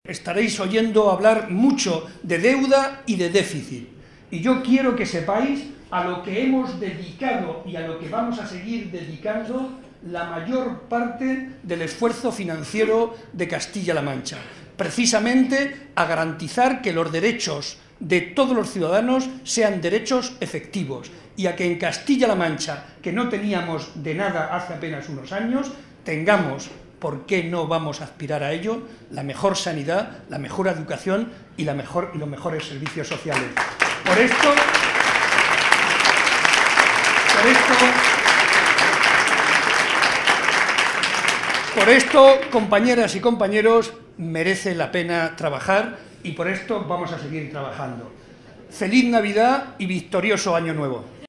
Estas palabras fueron pronunciadas por Barreda en el tradicional vino de Navidad organizado por la Agrupación Socialista de Ciudad Real, momento en el que aprovechó para reivindicar el papel fundamental de la educación para lograr los valores anteriormente mencionados.
Vino navideño PSOE Ciudad Real